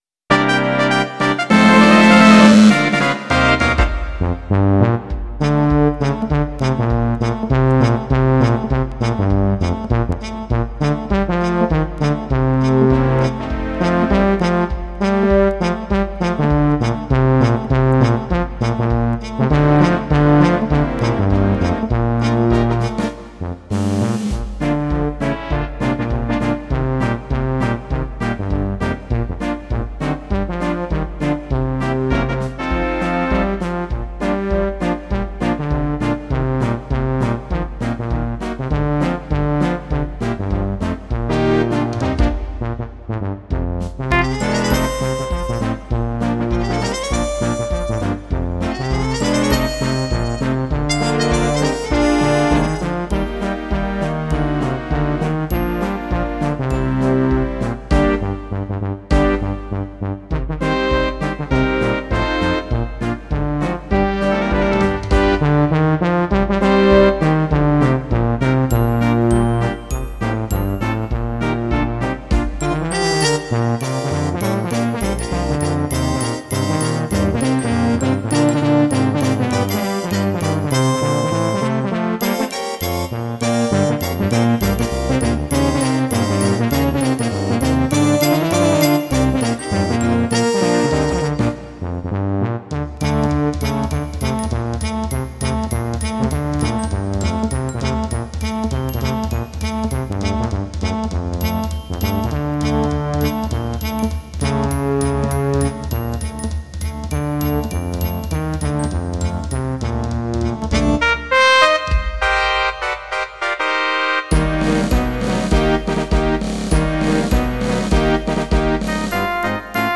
for brass band
of the up-tempo theme tune from the stage show and film.